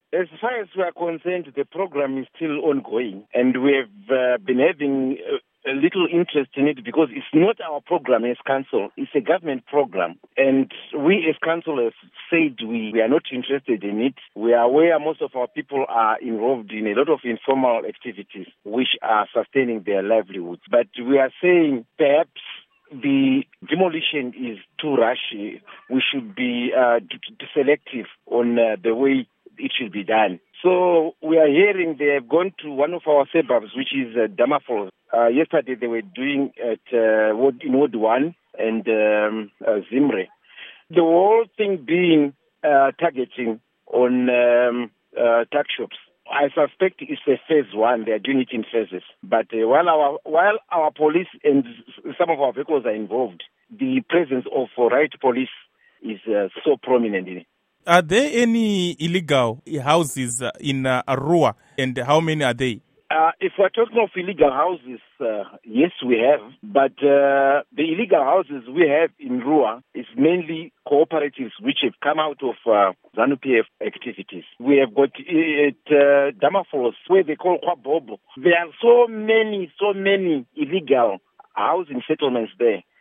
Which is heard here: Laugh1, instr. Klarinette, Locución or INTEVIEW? INTEVIEW